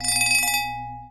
chime_bell_06.wav